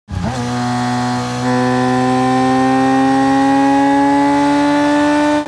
دانلود آهنگ موتور 5 از افکت صوتی حمل و نقل
دانلود صدای موتور 5 از ساعد نیوز با لینک مستقیم و کیفیت بالا
جلوه های صوتی